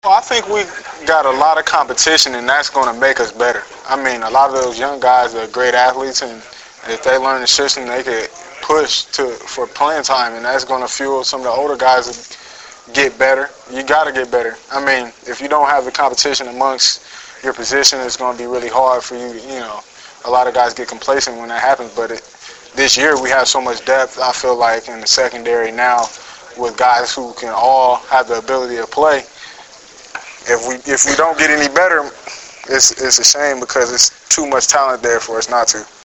Preseason Press Conference
Memorial Stadium - Lincoln, Neb.